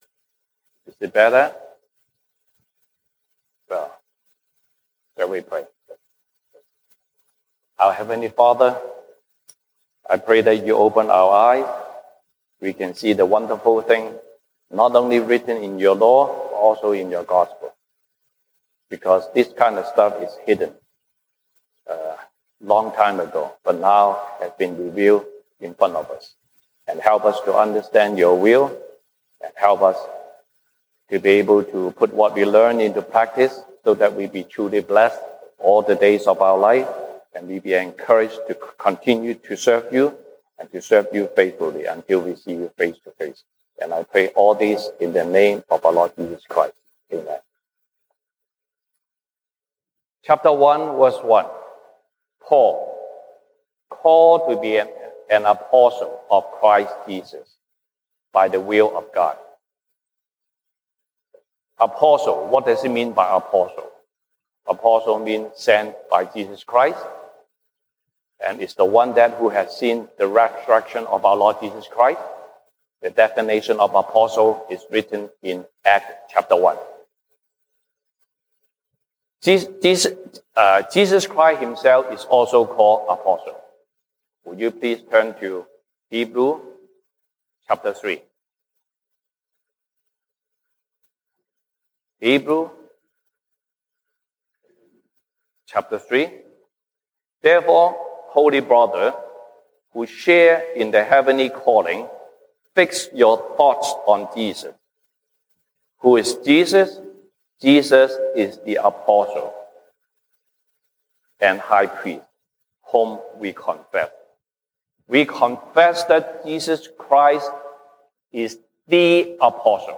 西堂證道 (英語) Sunday Service English: God who establishes you will establish to the end.
1 Corinthians Passage: 歌林多前書 1 Corinthians 1:1-9 Service Type: 西堂證道 (英語) Sunday Service English Topics